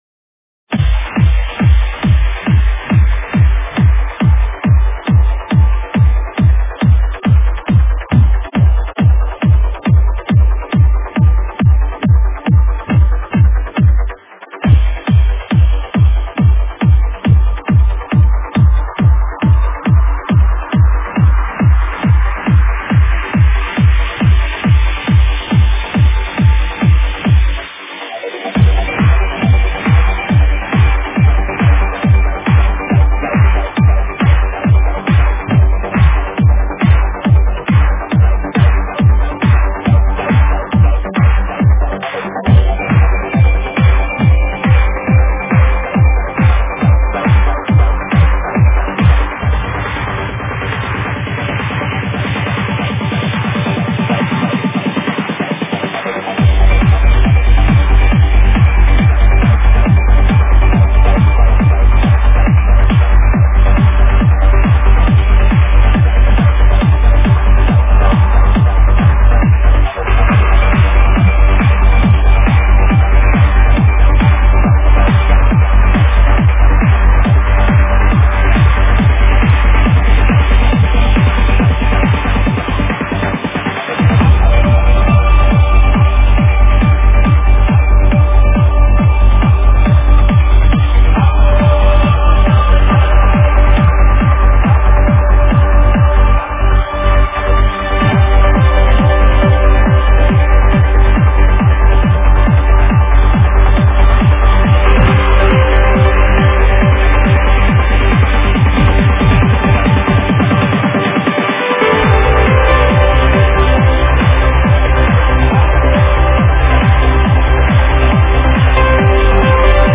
Стиль: Trance / Uplifting Trance